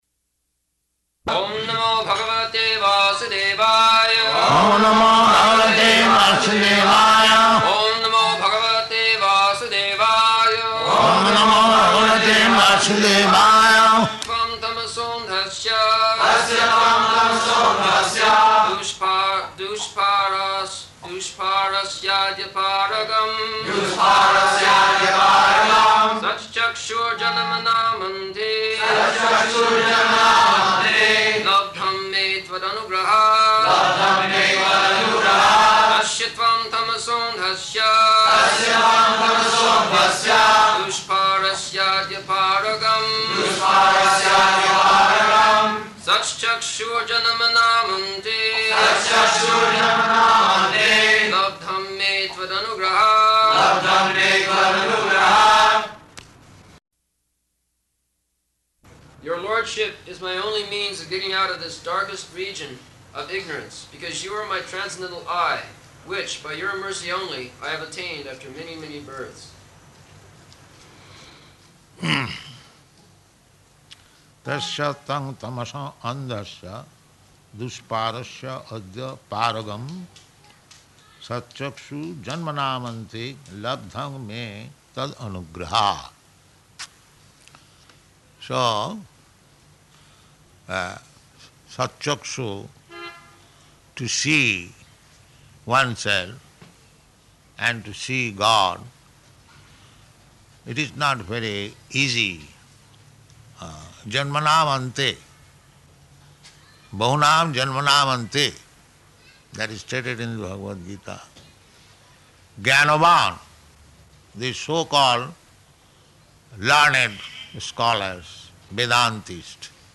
November 8th 1974 Location: Bombay Audio file
[Prabhupāda and devotees repeat] [leads chanting of verse, etc.]